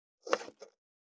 522厨房,台所,野菜切る,咀嚼音,ナイフ,調理音,まな板の上,料理,
効果音厨房/台所/レストラン/kitchen食器食材